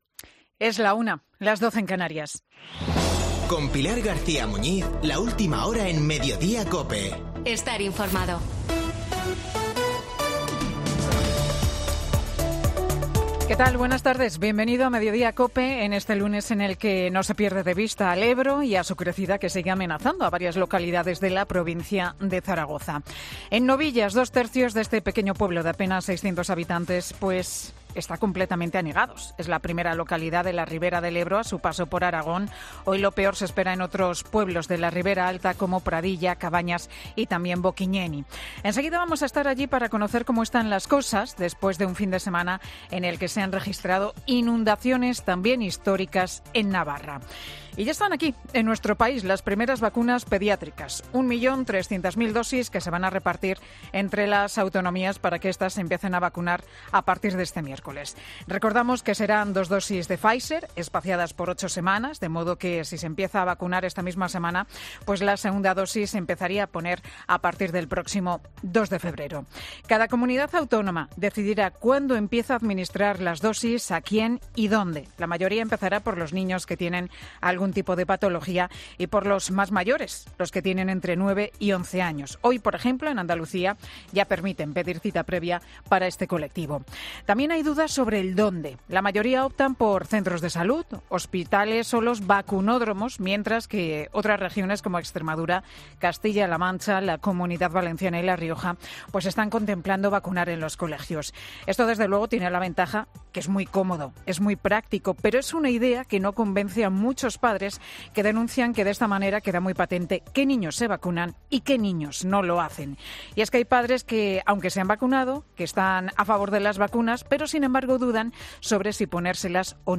AUDIO: El monólogo de Pilar García Muñiz en 'Mediodía COPE'